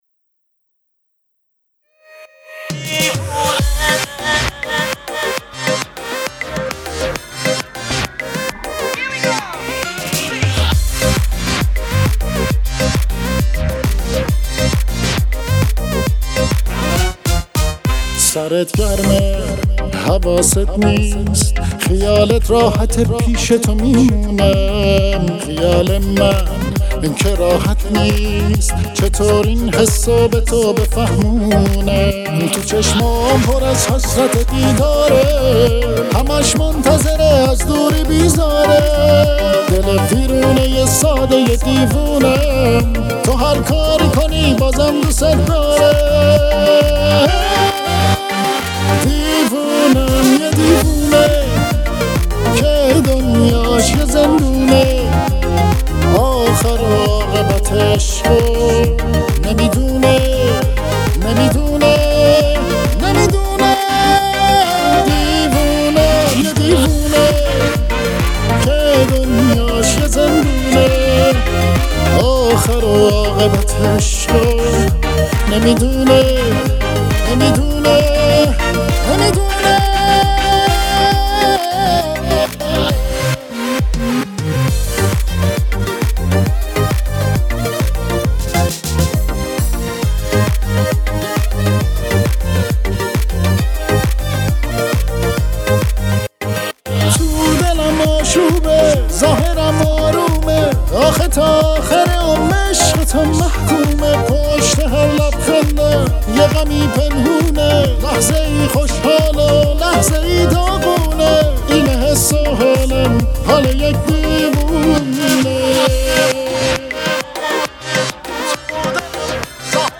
ریتم 6/8 شاد